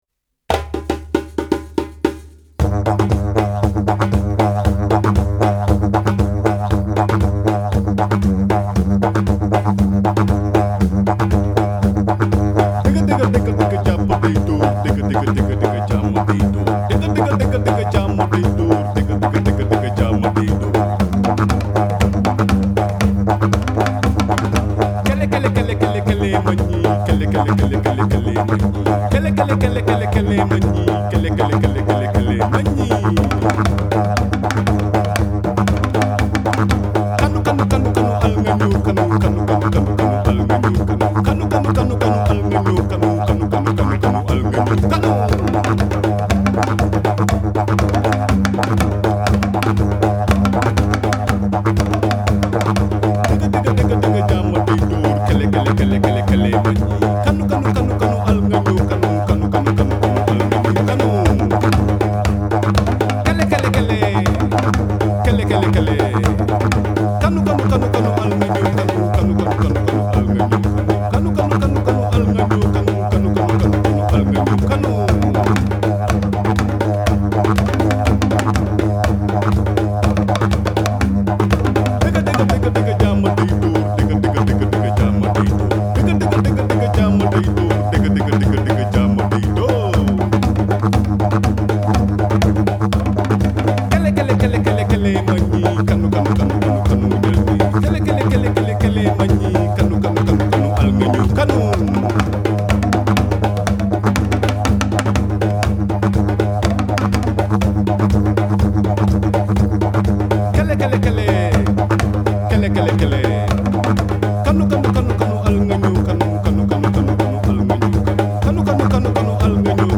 Die One Man Band
Drums, Sitar, Didgeridoo